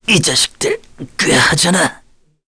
Mitra-Vox_Dead_kr_01.wav